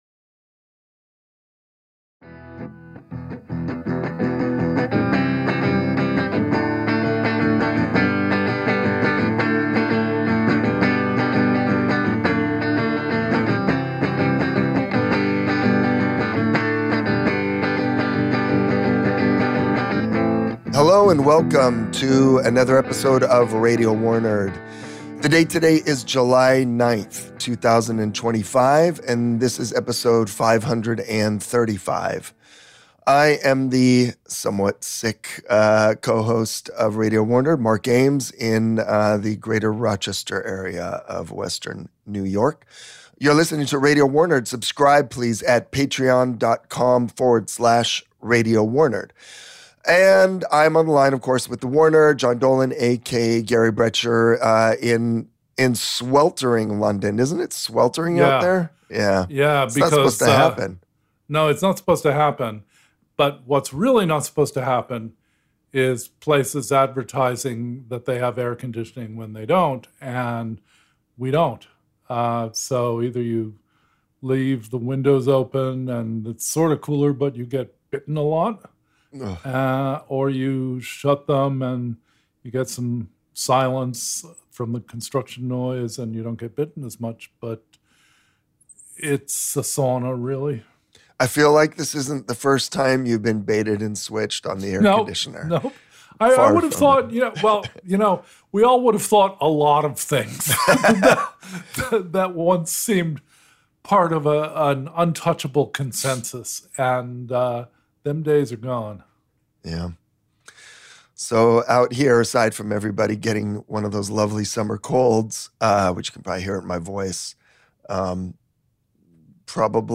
Music interlude